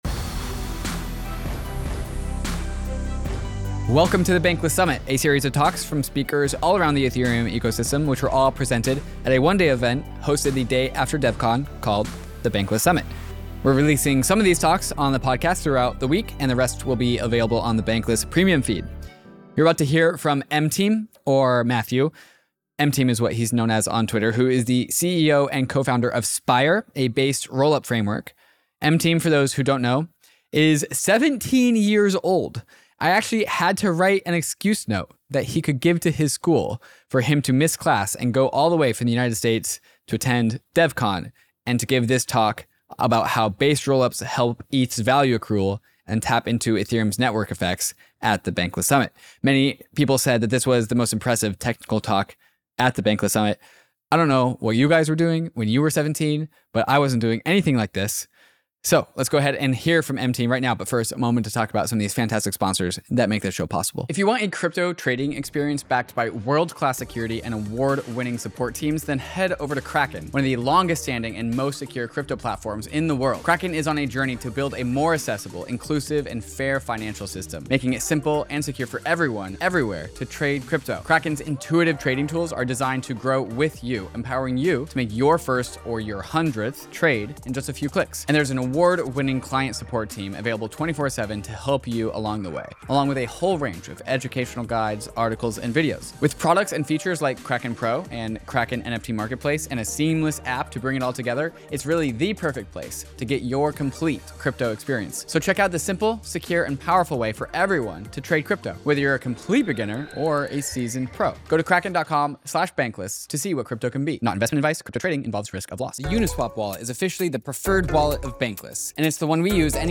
Many people said this was the most impressive technical talk at the Bankless Summit.